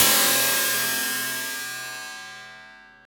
SI2 CRASH0GR.wav